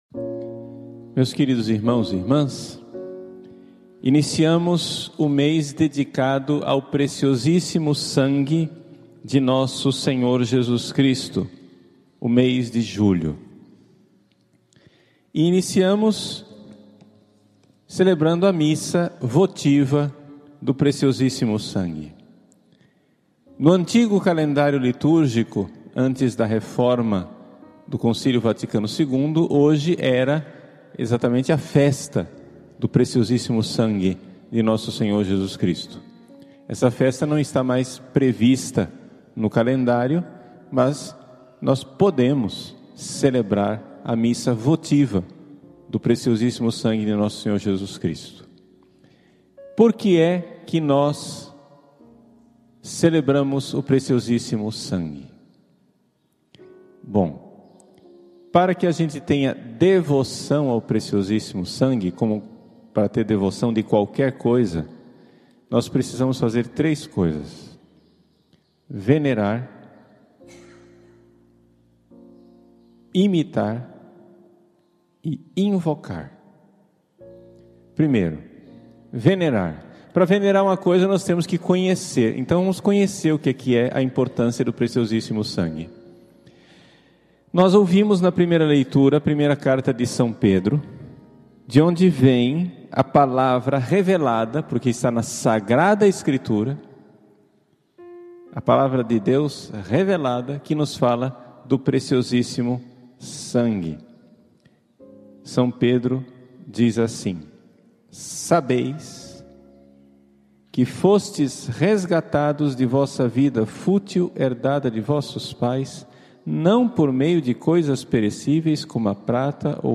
homilia especial
Missa rezada na manhã de hoje, na Paróquia Cristo Rei, de Várzea Grande (MT).